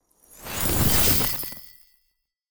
frozen_armor_12.wav